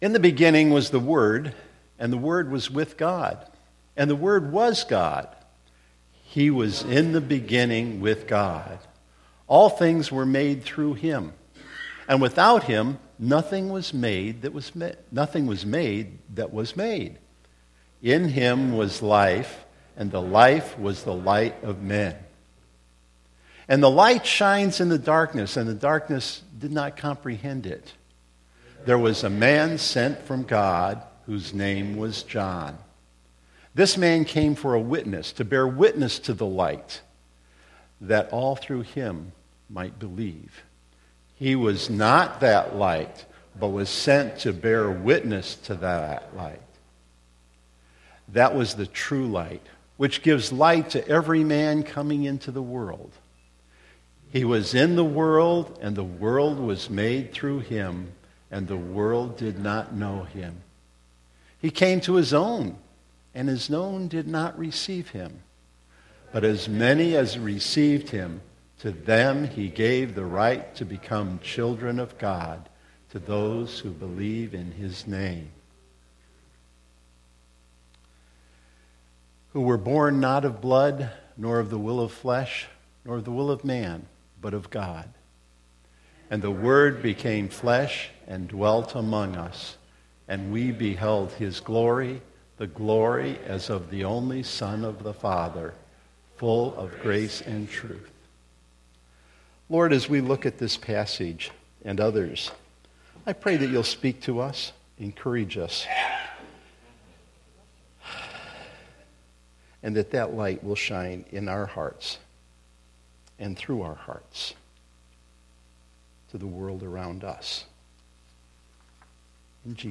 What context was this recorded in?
Christmas Day Service 2016